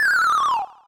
snd_fall2.wav